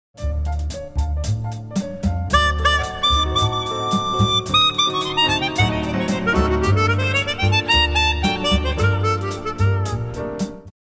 Секвенции
Приведем пример таких секвенций, для губной гармошки.